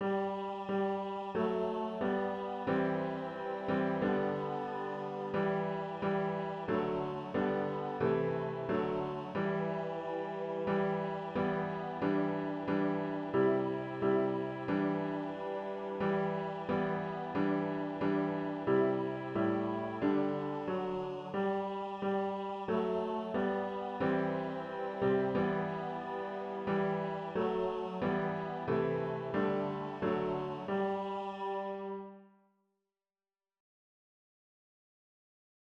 Good Friday Men’s Choir Pieces – Concordia Evangelical Lutheran Church
Gethsemane-ALL-PARTS.wav